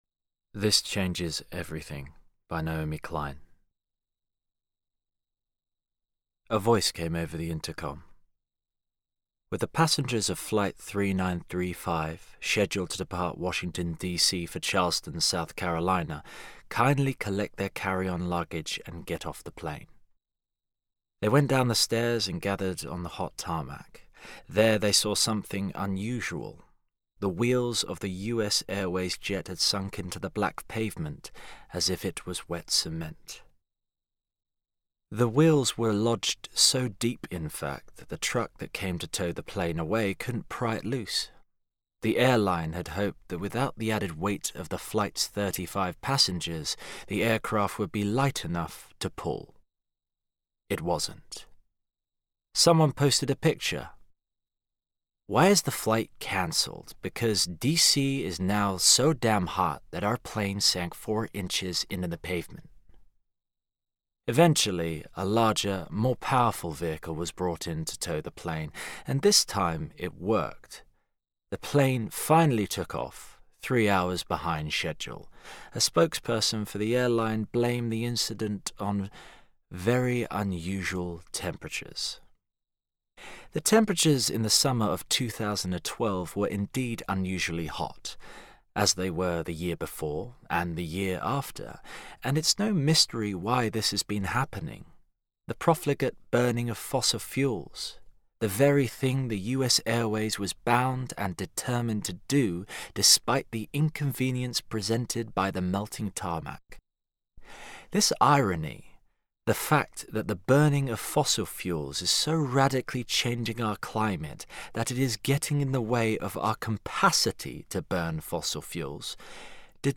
Voice Reel
Narrative Reel